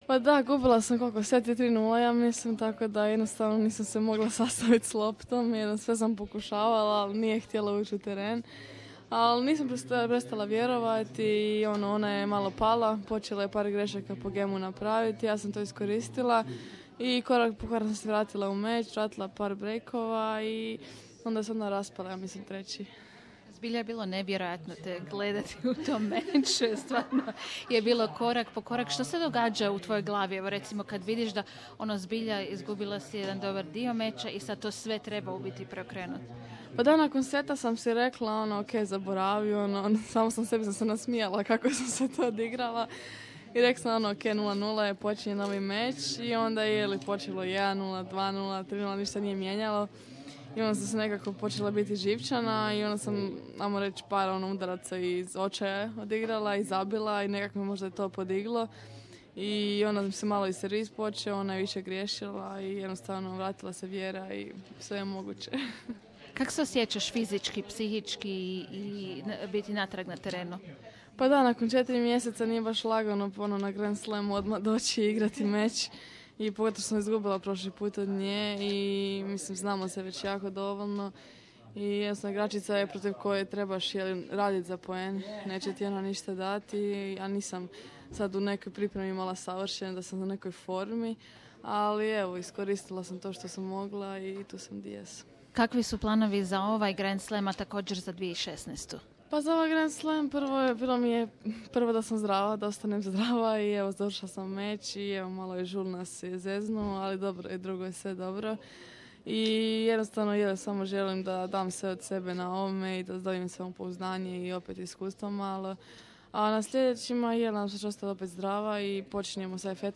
Interview with Ana Konjuh